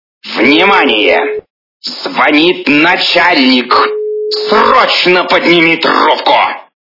При прослушивании Звонит начальник - Внимания звонит начальник...Срочно подними трубку качество понижено и присутствуют гудки.